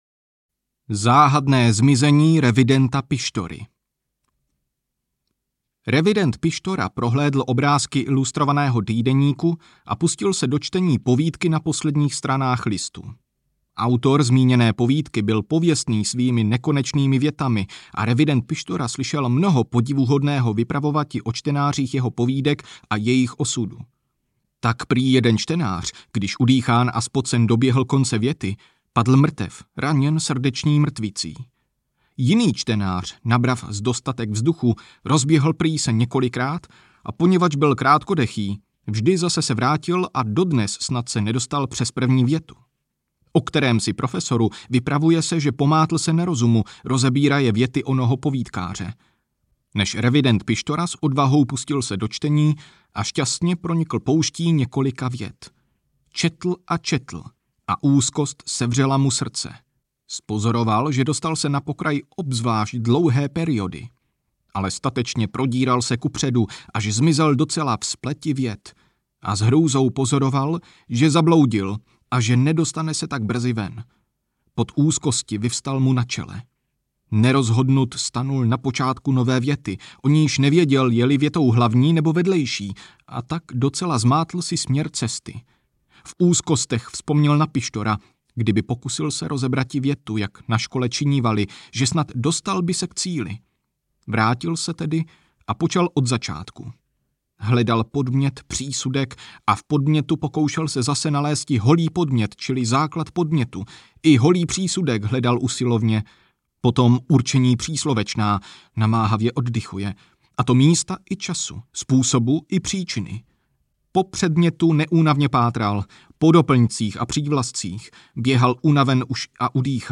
Satiry a ironické humoresky audiokniha
Ukázka z knihy